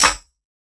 Snare 018.wav